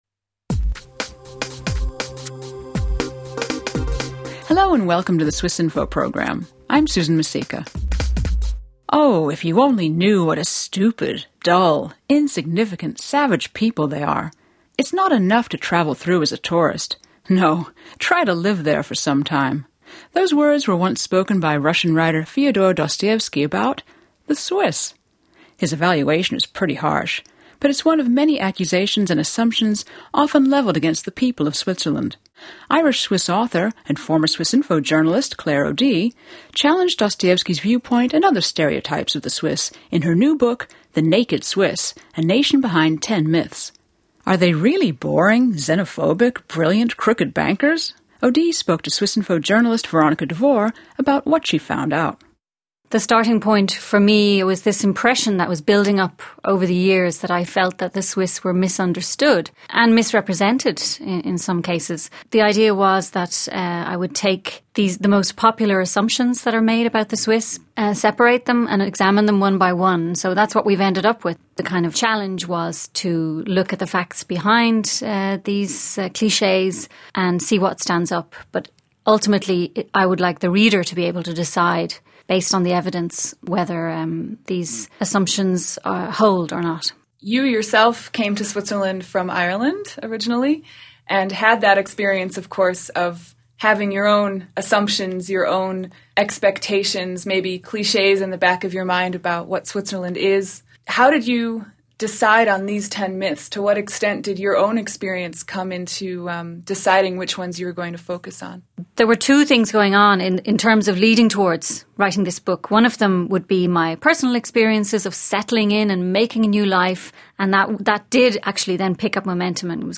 External link A conversation about the process and what she found out.